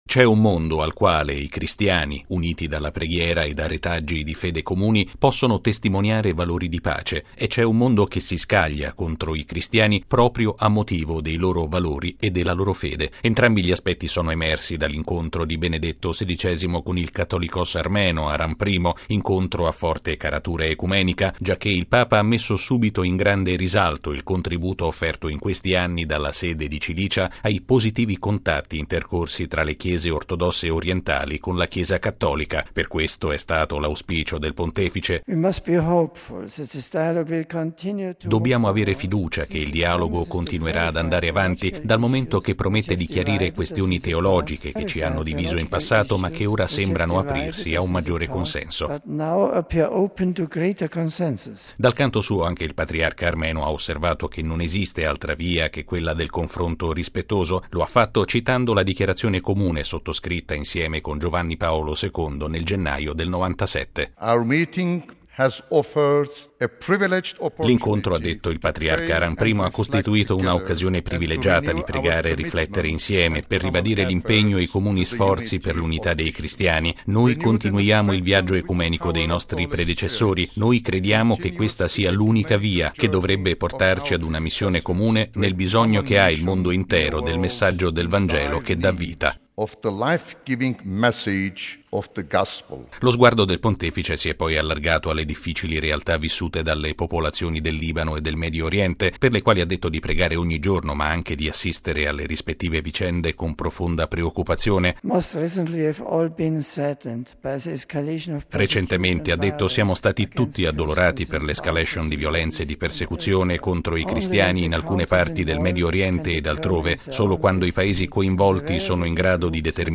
(canto in armeno)